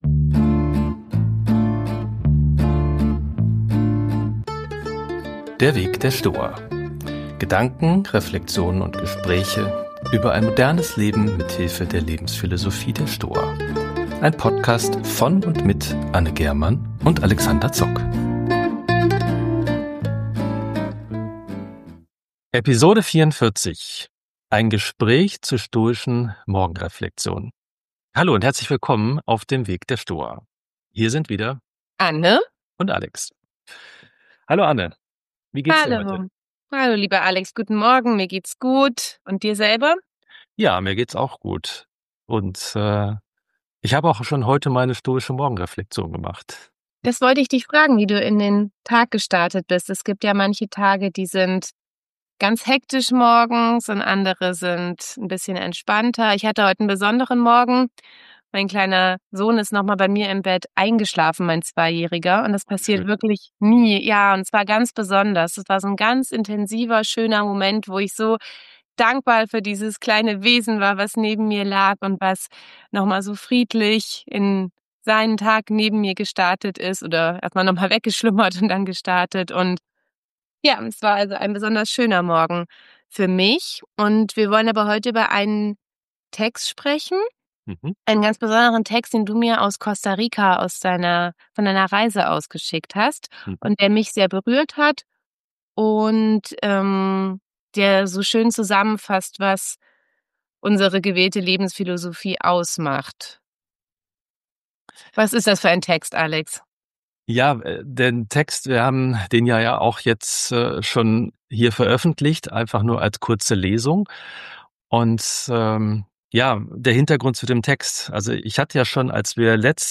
Episode 44 - Gespräch zur stoischen Morgenreflexion ~ Der Weg der Stoa Podcast